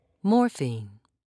(mor'feen)